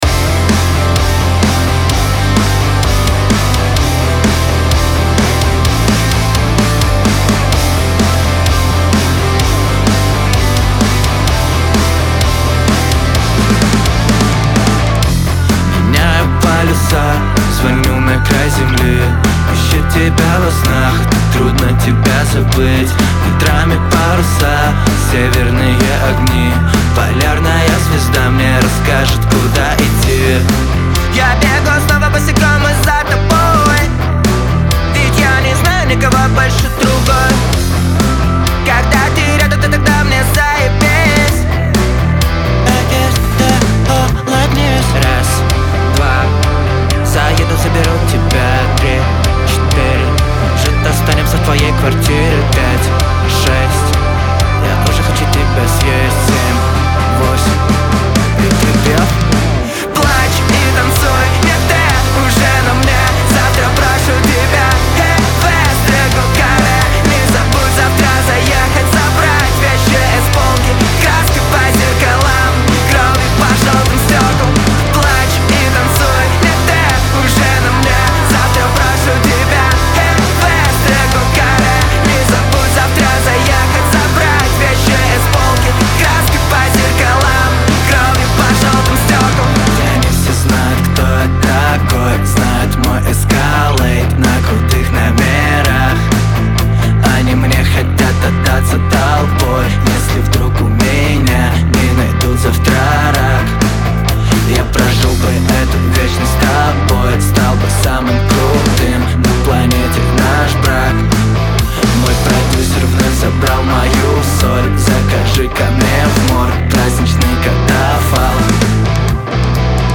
Трек размещён в разделе Русские песни / Эстрада.